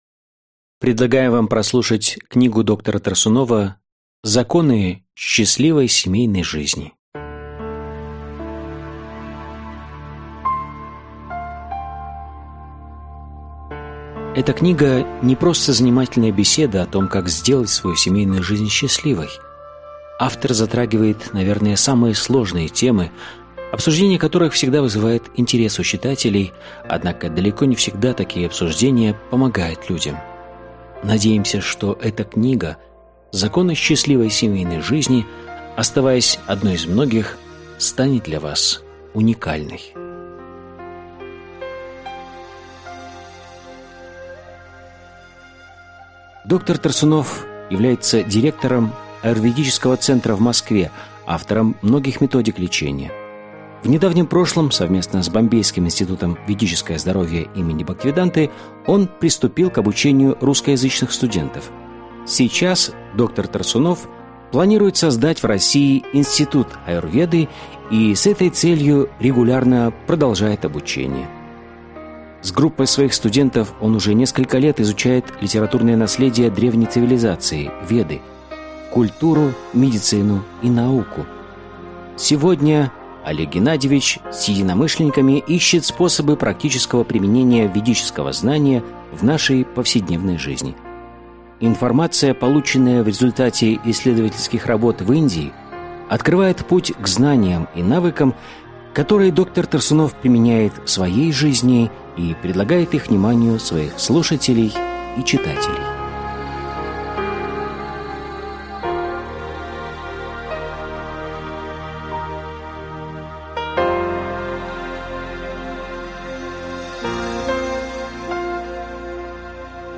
Аудиокнига Законы счастливой семейной жизни. Часть 2 | Библиотека аудиокниг